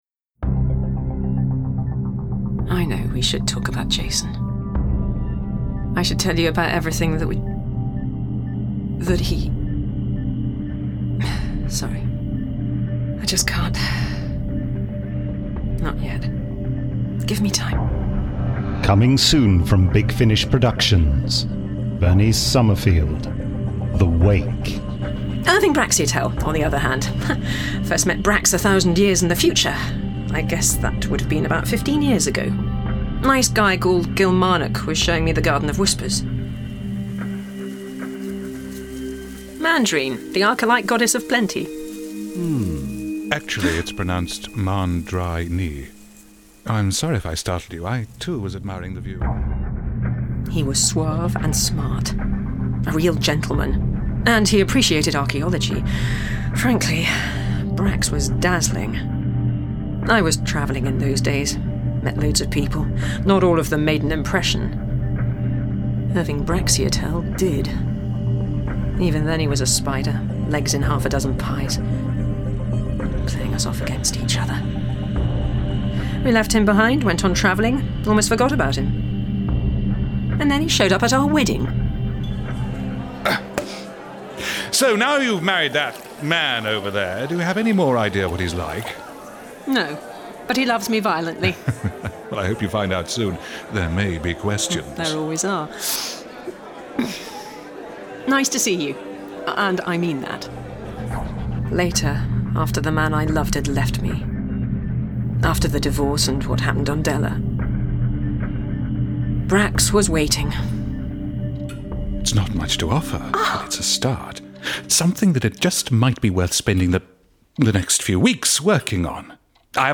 Starring Lisa Bowerman Miles Richardson